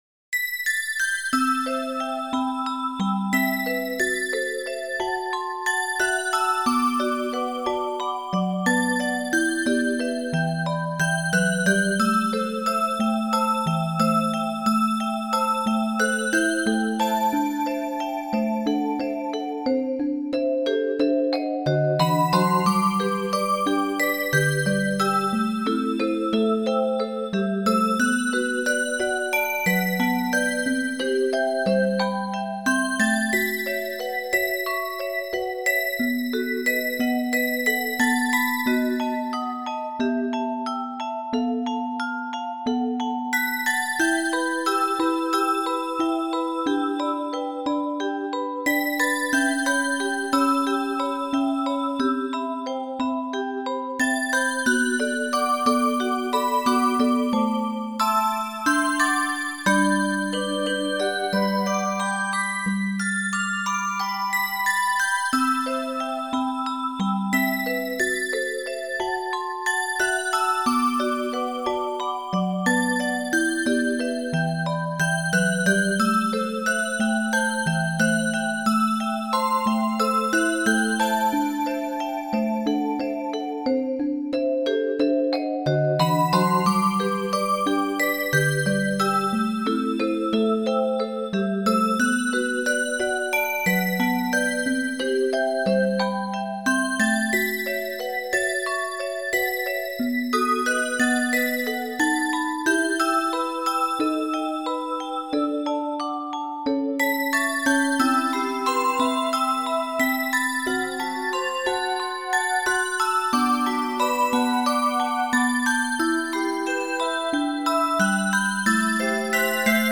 2025/7/5 オルゴール風アレンジです。
そんな感じのヒーリングミュージック風なアレンジです。